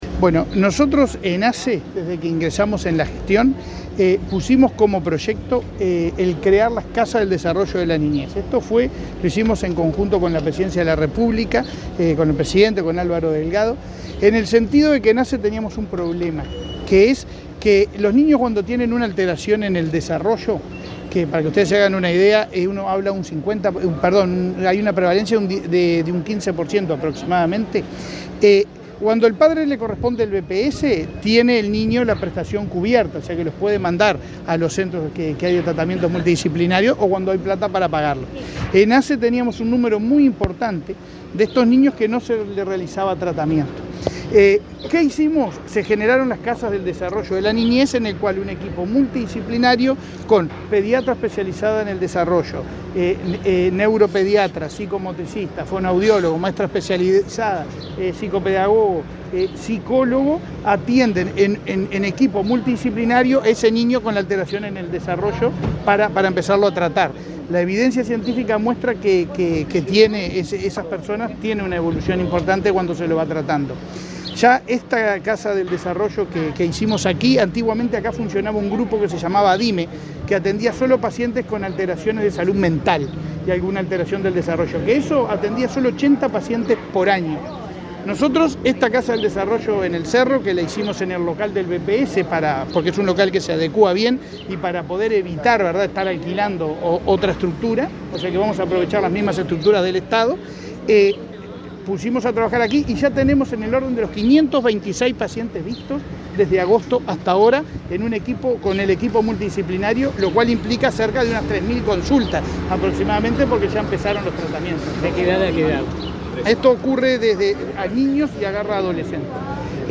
Declaraciones a la prensa del presidente de ASSE, Leonardo Cipriani
El presidente de la Administración de los Servicios de Salud del Estado (ASSE), Leonardo Cipriani, dialogó con la prensa, antes de participar del acto